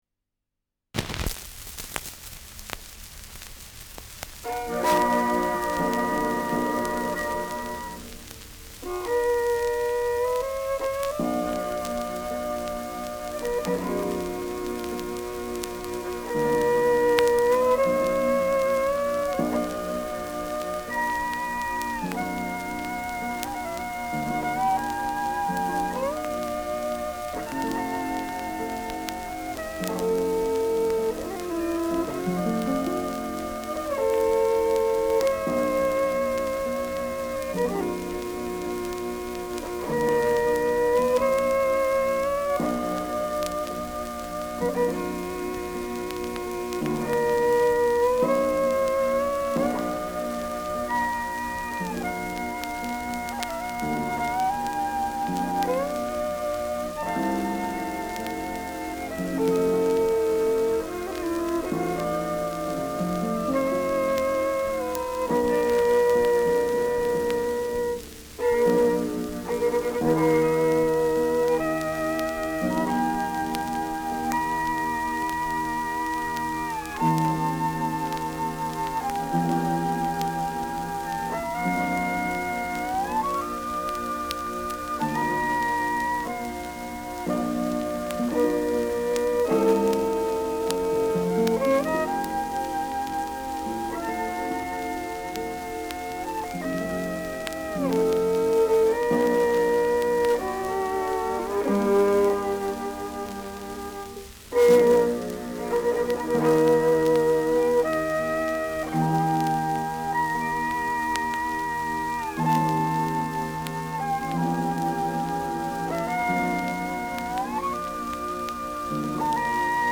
Schellackplatte
Tonrille: Berieb 1 -3 Uhr Leicht
präsentes Rauschen